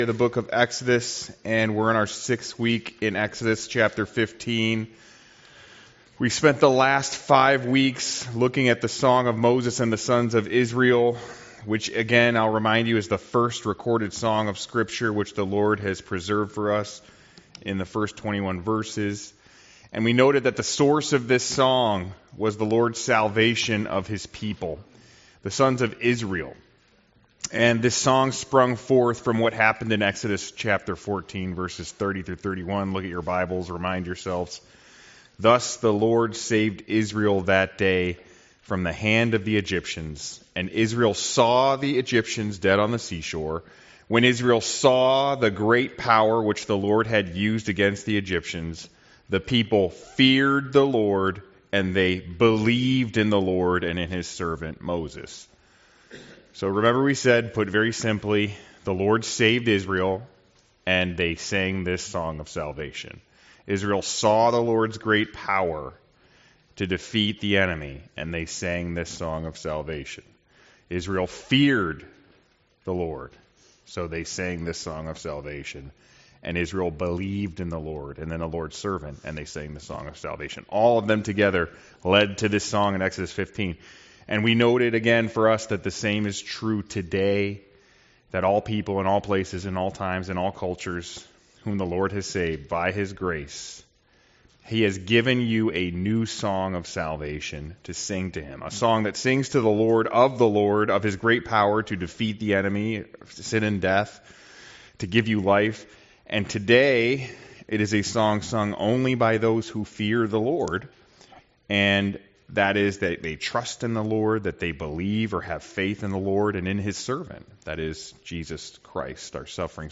Passage: Exodus 5 Service Type: Sunday School « WMBS